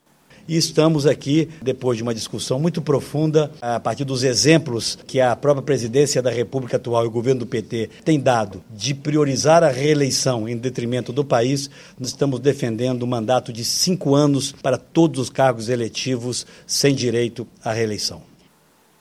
Fala do senador Aécio Neves